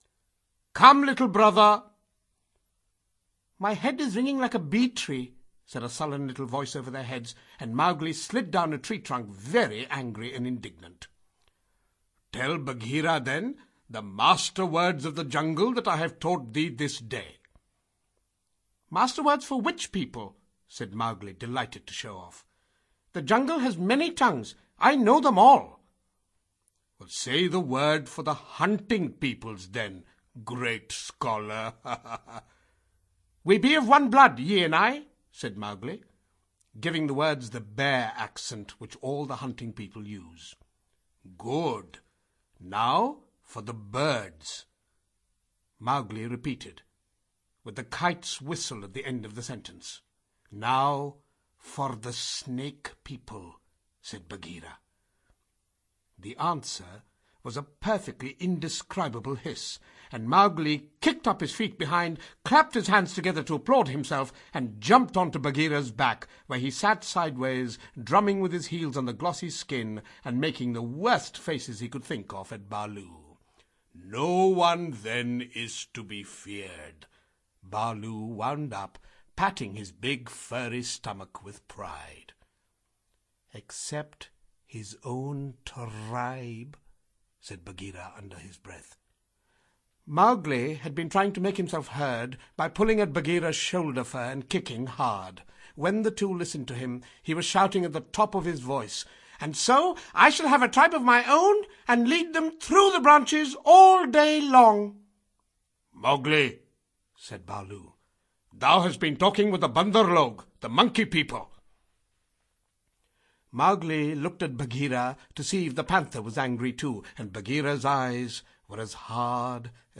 The Jungle Books (EN) audiokniha
Celý popis Rok vydání 2013 Audio kniha Zkrácená verze Ukázka z knihy 225 Kč Koupit Ihned k poslechu – MP3 ke stažení Potřebujete pomoct s výběrem?